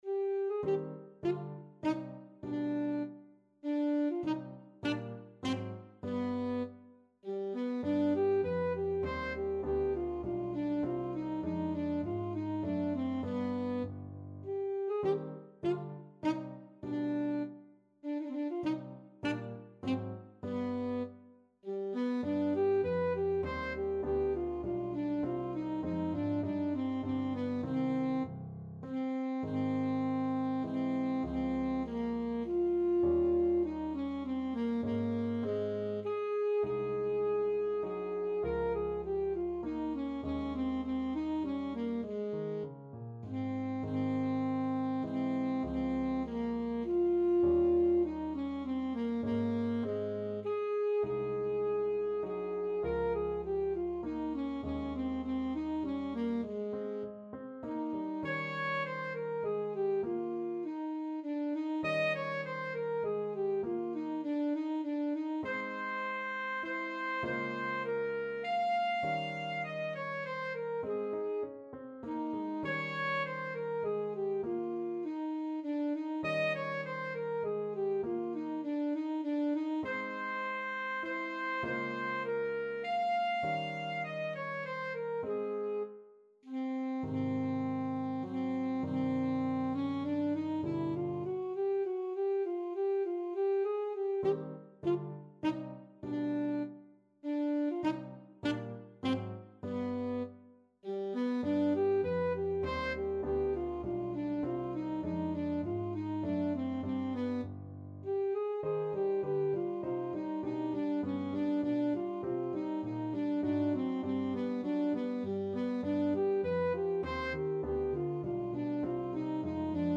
Alto Saxophone
3/4 (View more 3/4 Music)
Allegretto
Classical (View more Classical Saxophone Music)